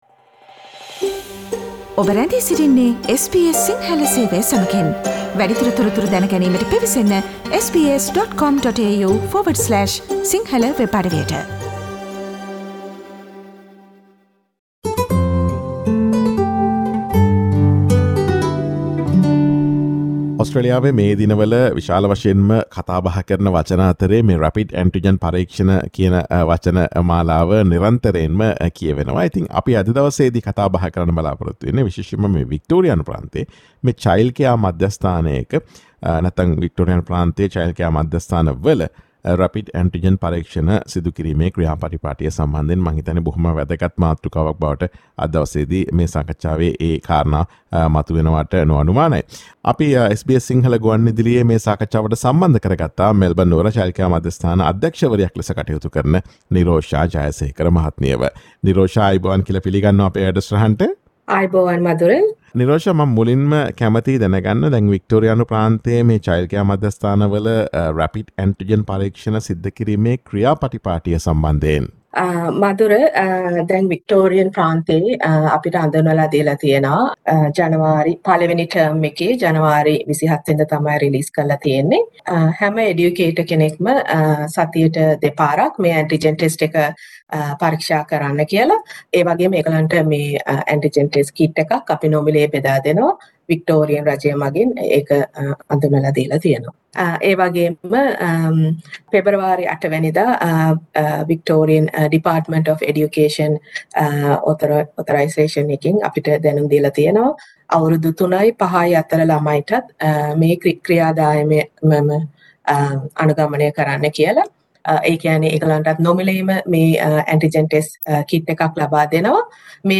වික්ටෝරියා ප්‍රාන්තයේ Childcare මධ්‍යස්ථානවල Rapid Antigen පරීක්ෂණ සිදු කිරීමේ ක්‍රියා පටිපාටිය පිළිබඳ SBS සිංහල ගුවන් විදුලිය සිදුකළ සාකච්ඡාවට සවන් දෙන්න.